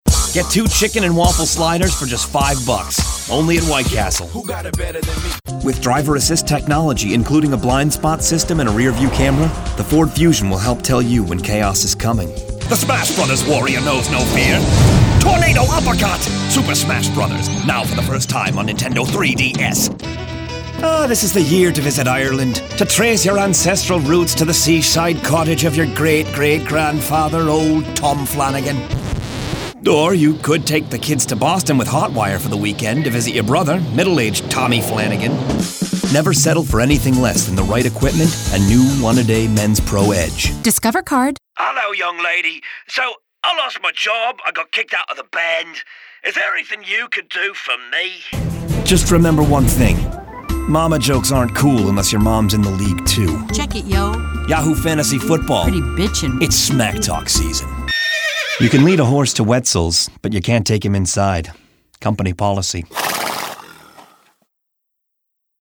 Download Voiceover Demo